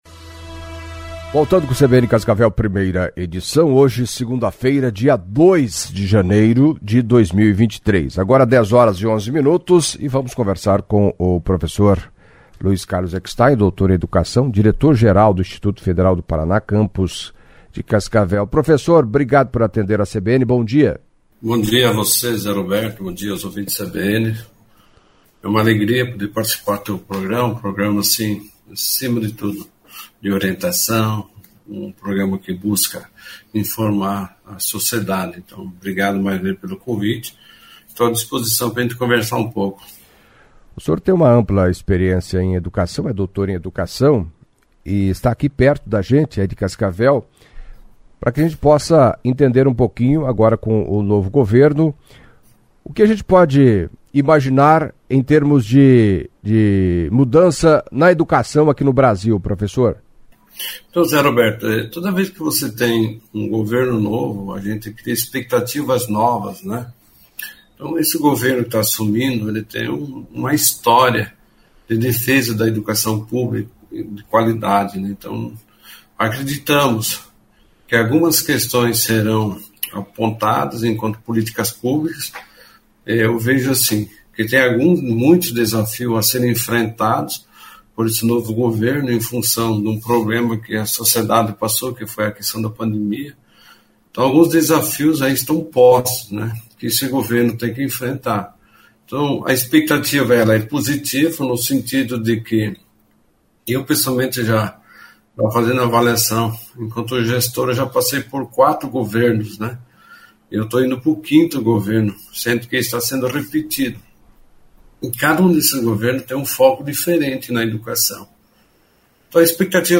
Em entrevista à CBN Cascavel nesta segunda-feira (02)